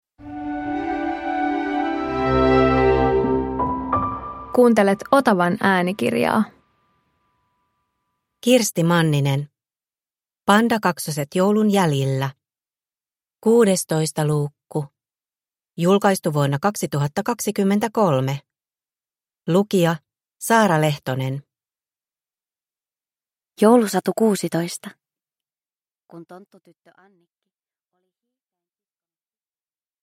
Pandakaksoset joulun jäljillä 16 – Ljudbok